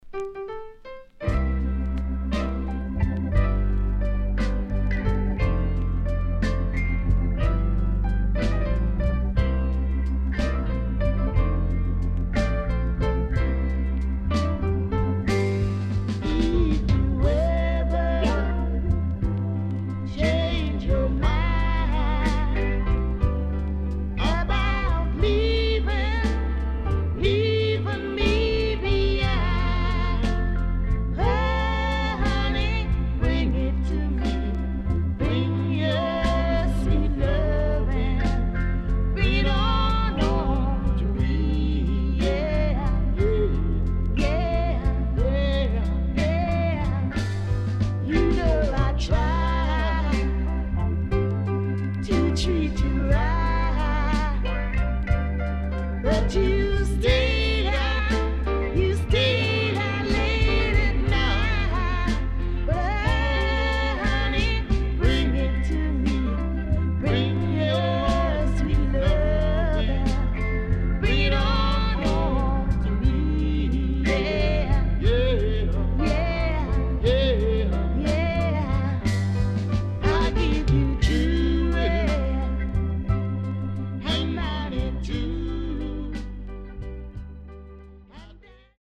SIDE B:少しノイズ入ります。